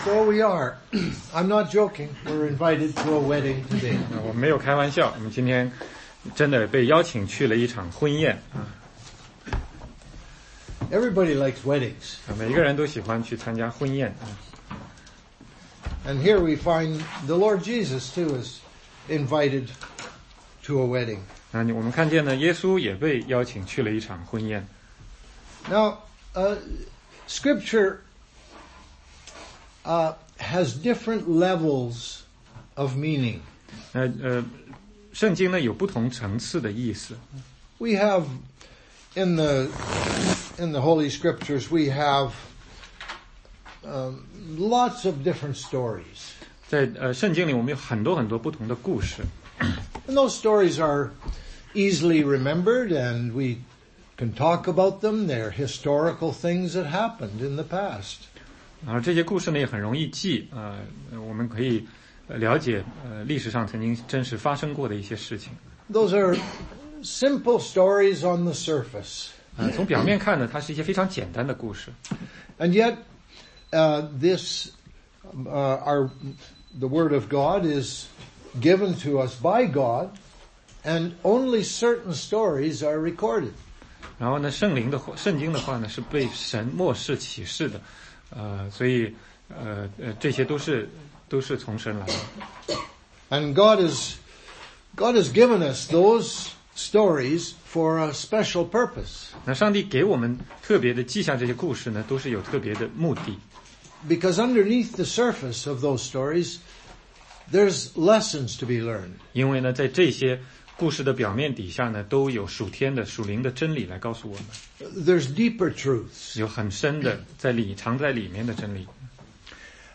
16街讲道录音 - 约翰福音2章1-11